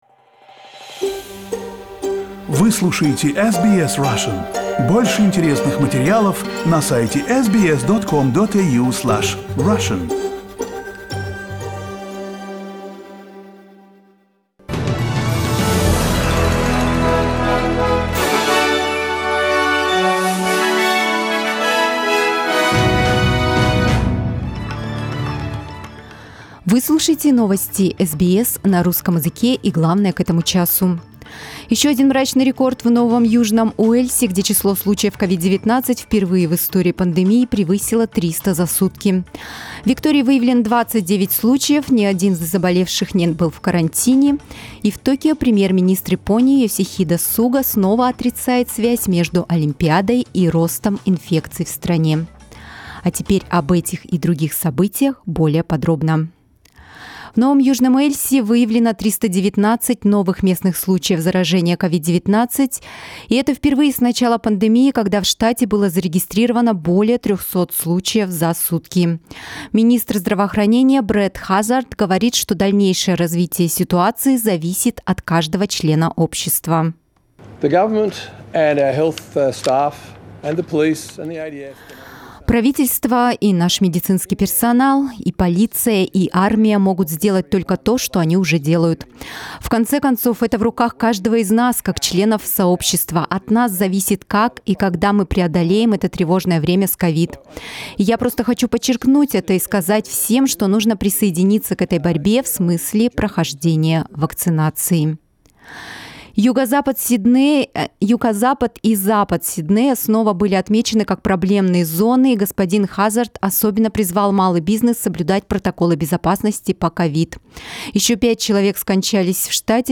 Новости SBS на русском языке - 7.08